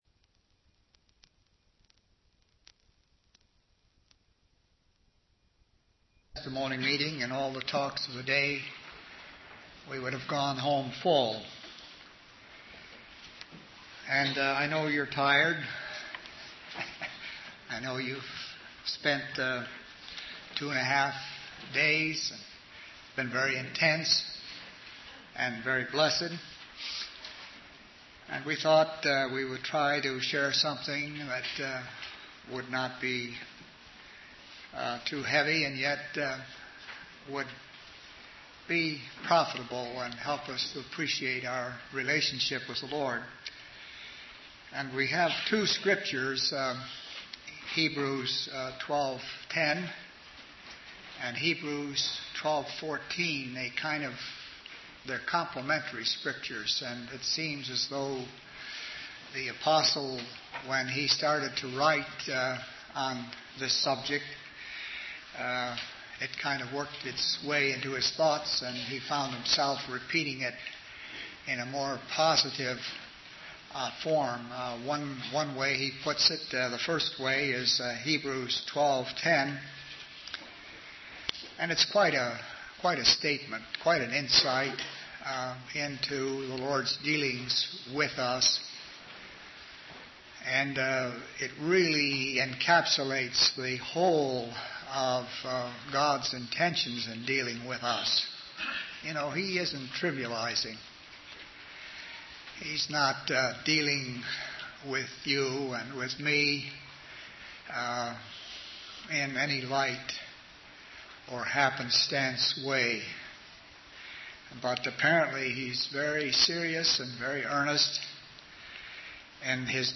From Type: "Discourse"
Given in Winnipeg, Canada in 1989